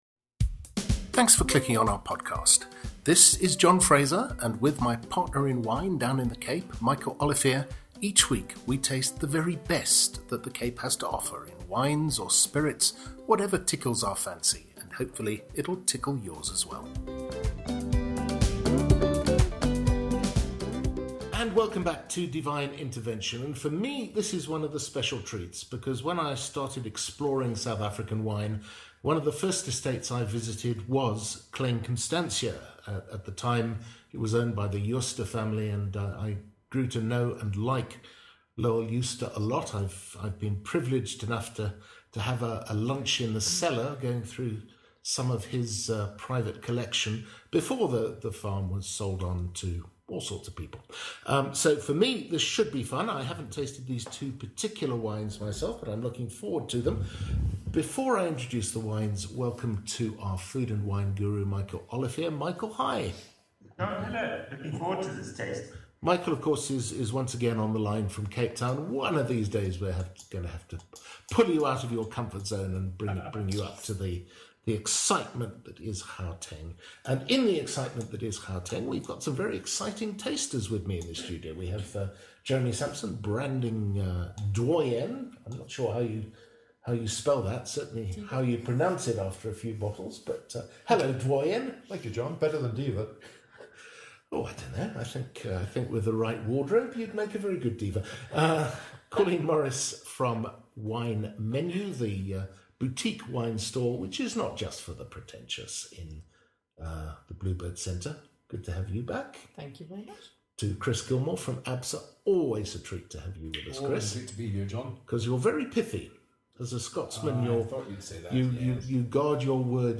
welcomes a quartet of quality to the Johannesburg studio